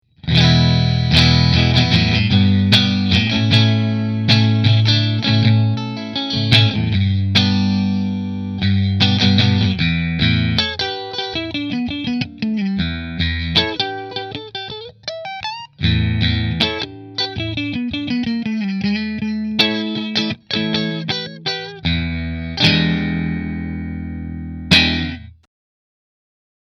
Back is made of Sassafrass which is similar to alder in tone.
No shrill high end. A real smooth guitar!
Rahan Guitars RP Single Cutaway Ambrosia Position 2 Through Fender